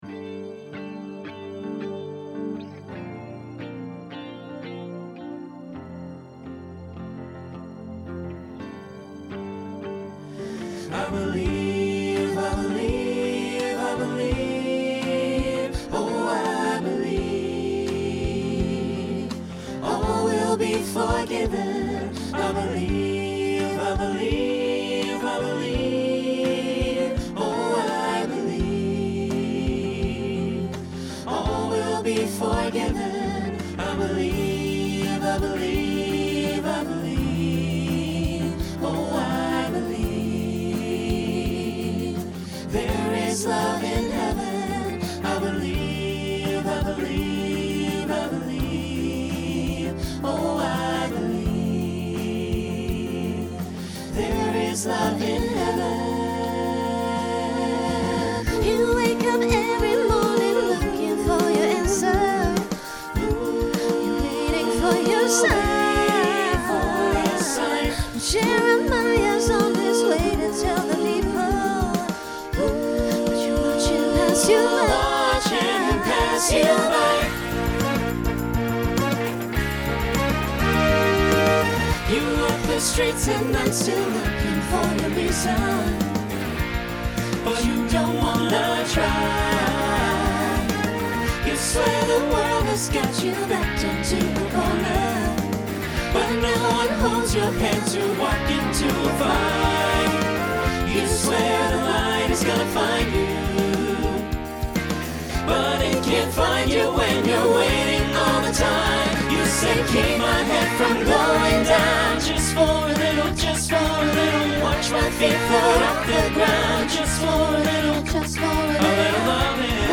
Voicing SATB Instrumental combo Genre Broadway/Film , Rock
2010s Show Function Mid-tempo